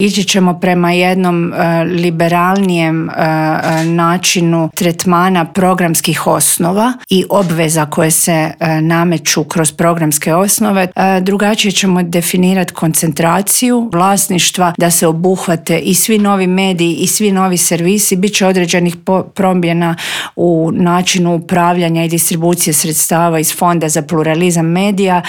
Jedna od uzvanica je ministrica kulture Nina Obuljen Koržinek s kojom smo o forumu, ali i Zakonu o elektroničkim medijima i primjerima dobre prakse medijske regulative u drugim eurpskim zemljama razgovarali u Intervjuu tjedna Media servisa.